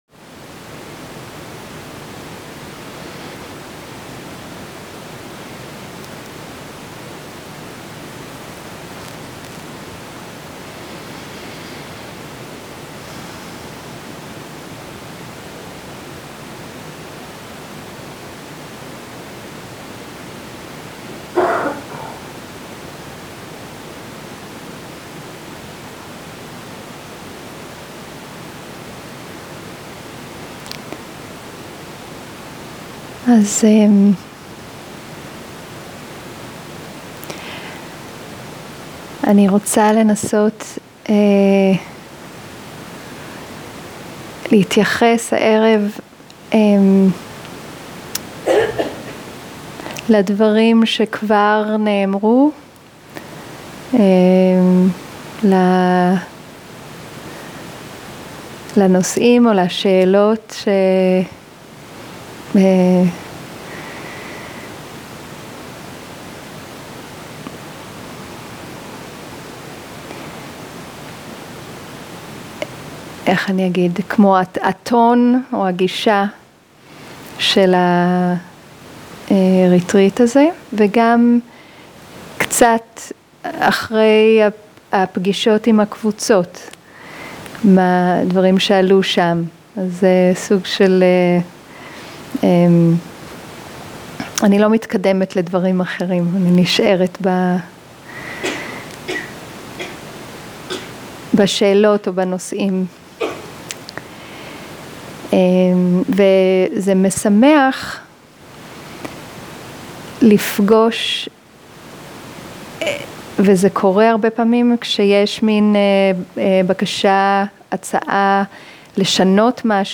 שיחת דהרמה - ערב שני
Dharma type: Dharma Talks